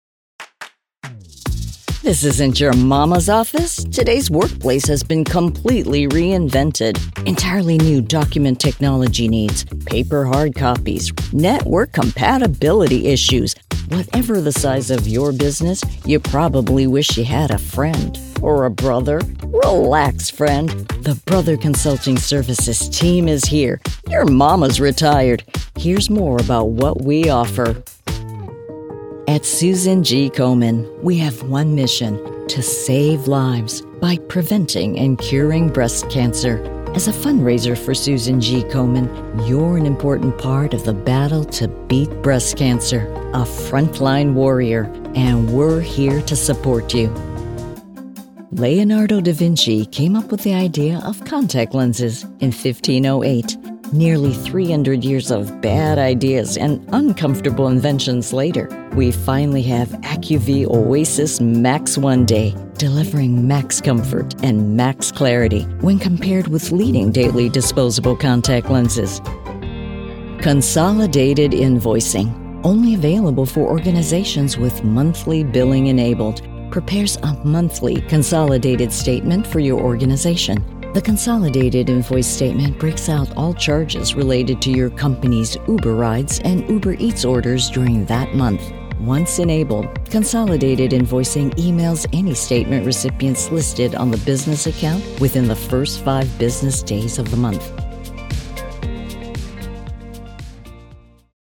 CORPORATE Reel